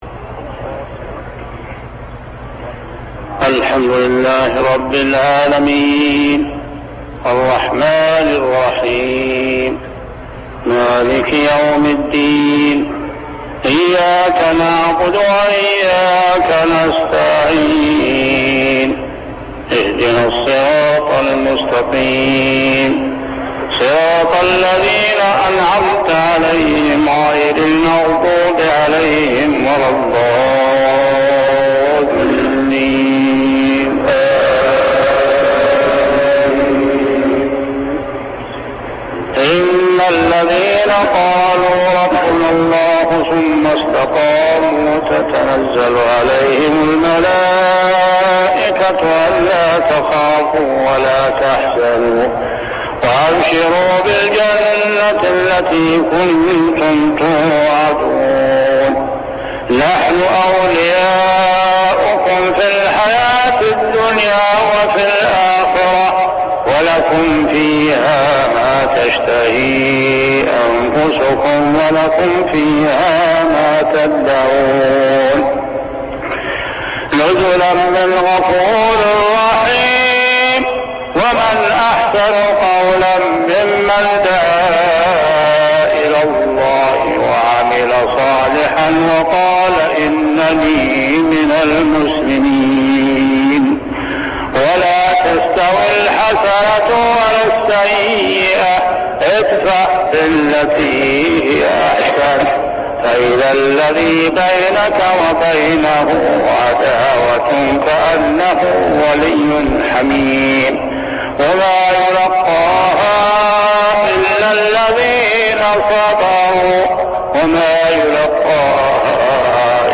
صلاة المغرب عام 1401هـ سورتي فصلت 30-36 و الفتح 27-28 | maghrib prayer Surah Fussilat and Al-Fath > 1401 🕋 > الفروض - تلاوات الحرمين